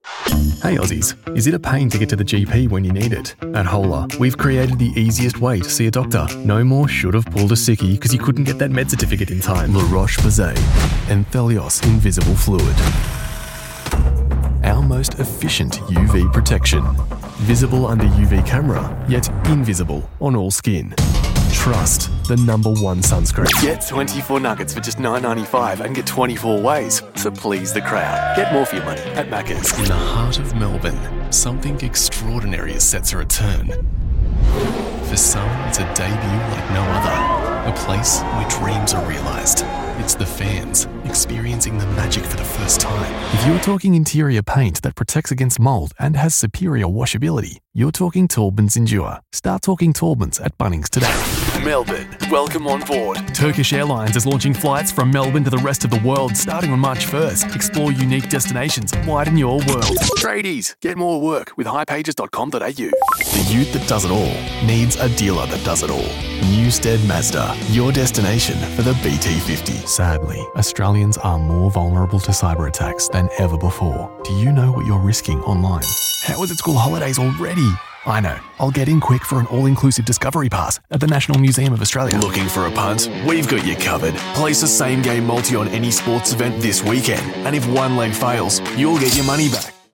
Fresh, friendly & warm, with a dash of Aussie larrikan.